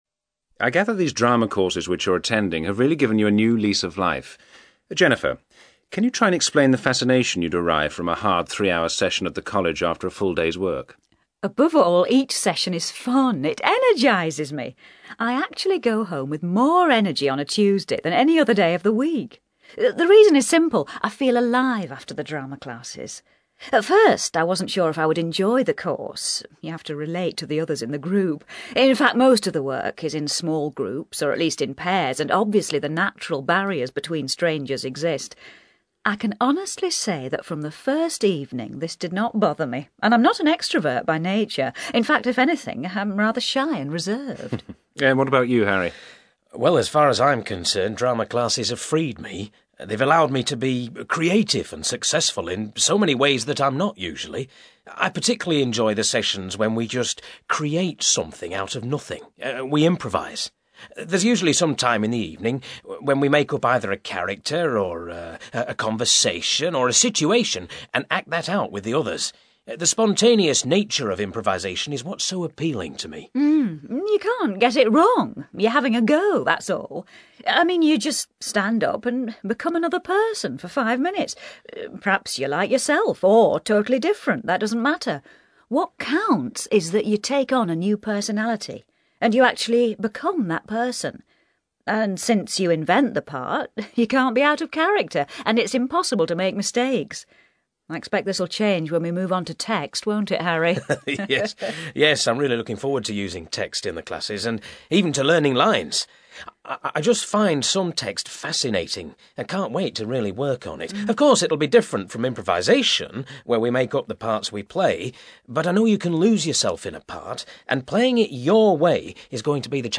Conversation with multiple-choice questions.